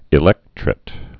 (ĭ-lĕktrĭt)